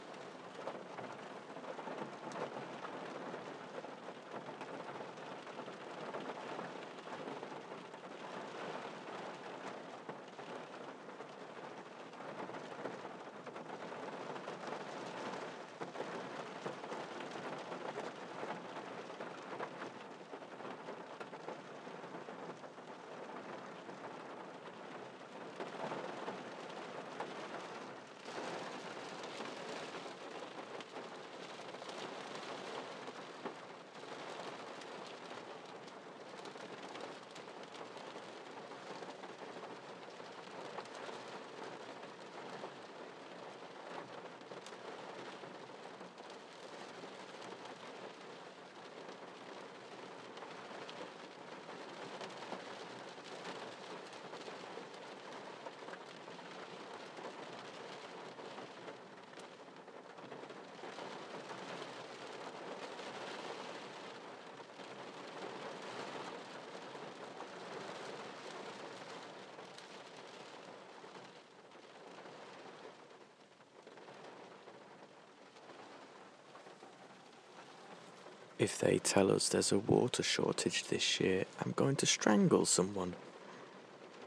sound bite of the rain
Weather, rain, british, gloom, water shortage